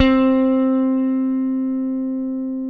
Index of /90_sSampleCDs/AKAI S-Series CD-ROM Sound Library VOL-7/JAZZY GUITAR
JAZZ GT1C3.wav